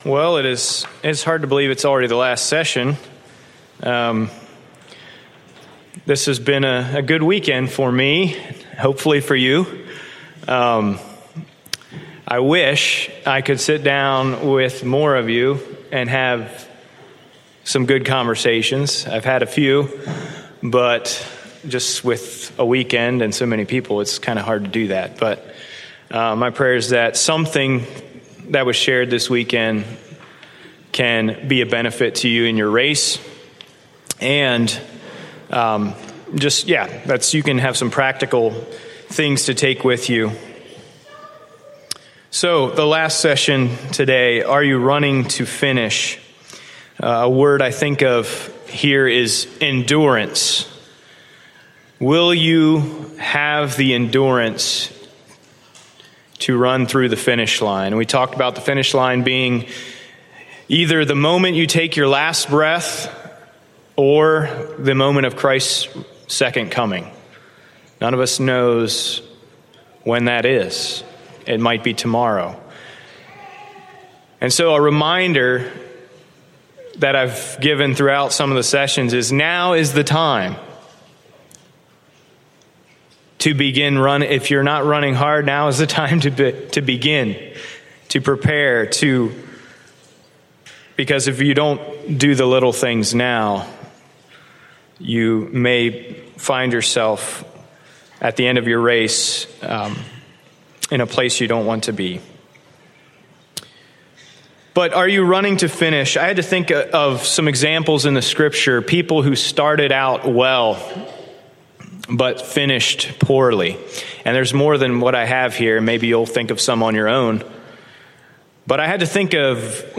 Conservative Christian Sermons - Bethel Mennonite Church